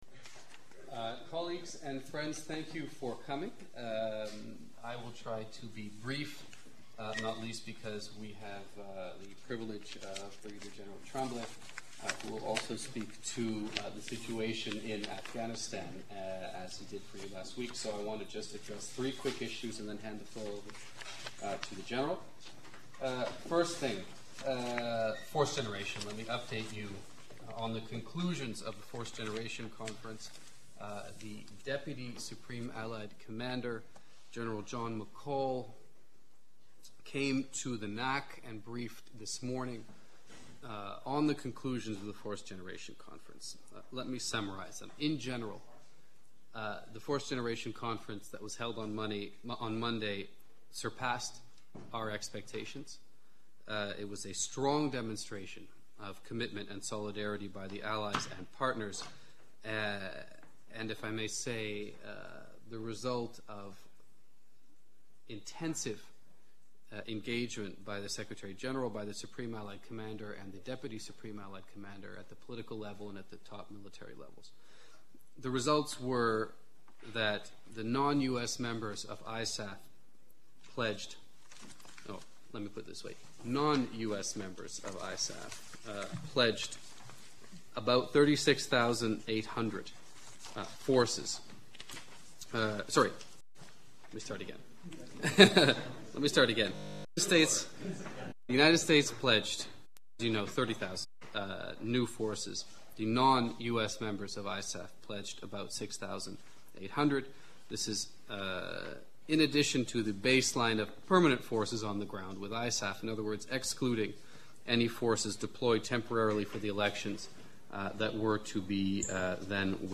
Press briefing